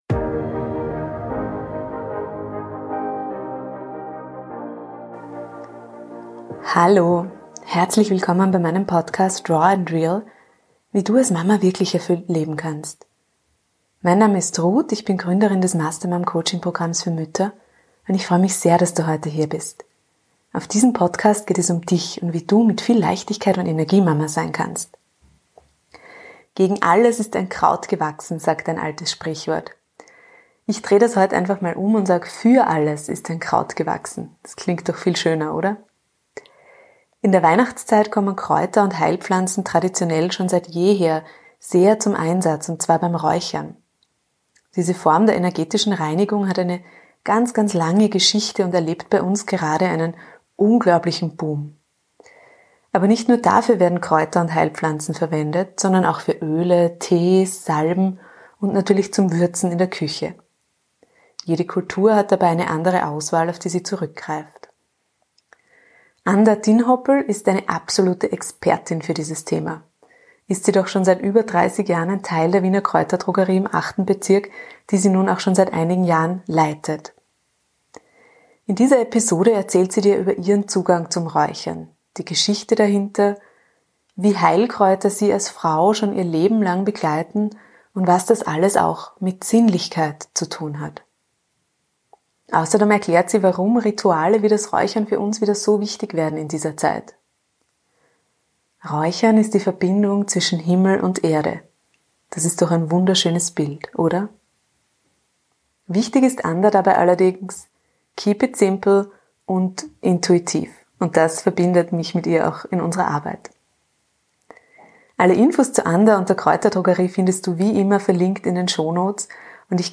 #79 Das alte Wissen der Heilpflanzen neu entdeckt .Interview